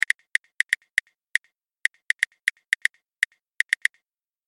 جلوه های صوتی
دانلود صدای تلفن 9 از ساعد نیوز با لینک مستقیم و کیفیت بالا